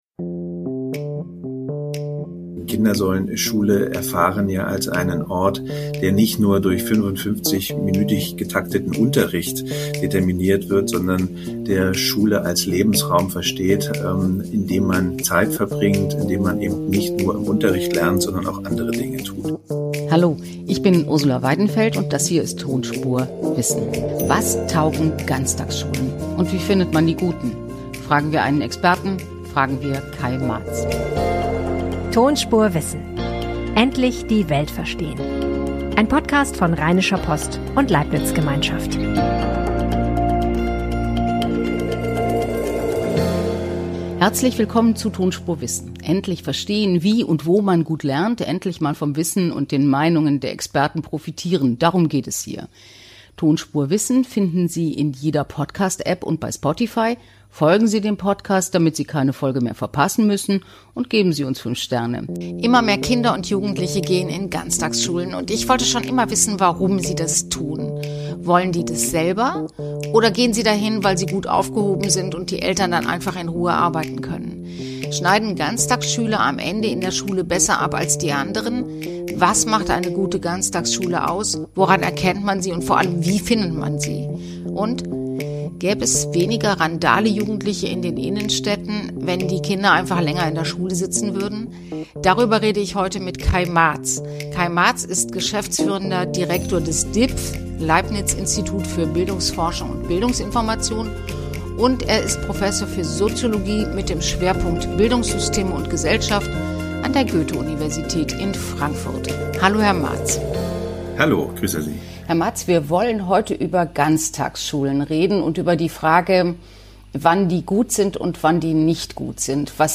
im Gespräch.